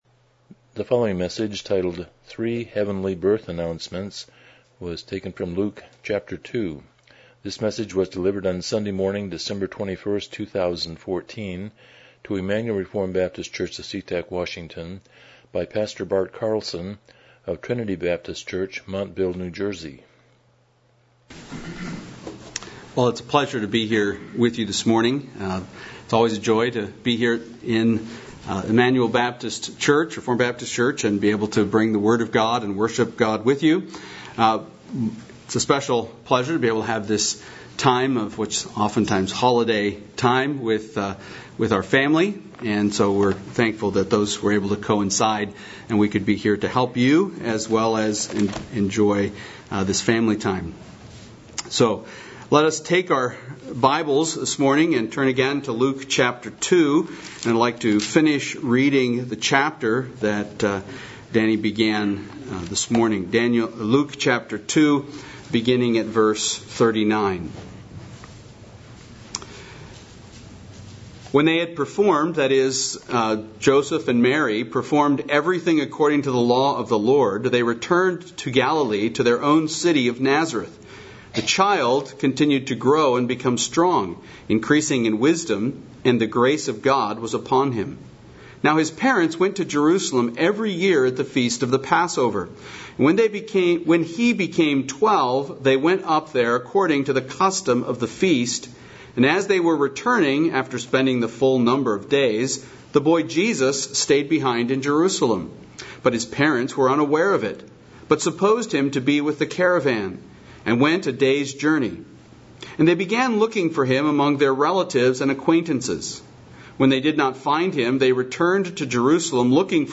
Miscellaneous Service Type: Morning Worship « Finding Christ in the Old Testament